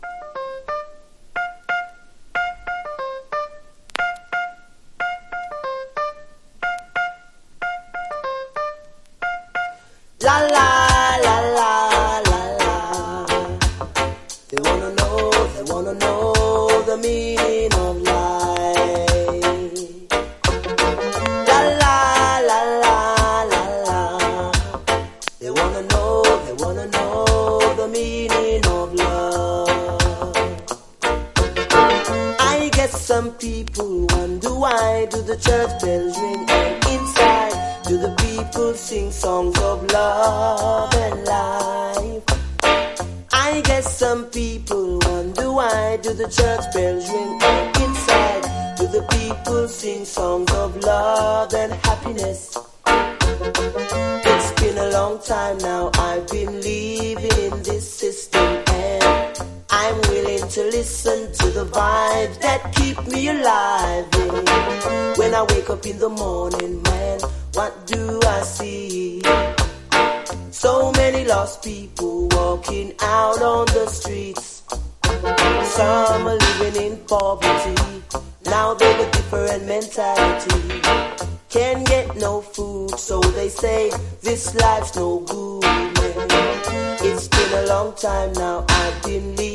80年代後期にリリースされたUKコンシャス・ラバダブ･チューン！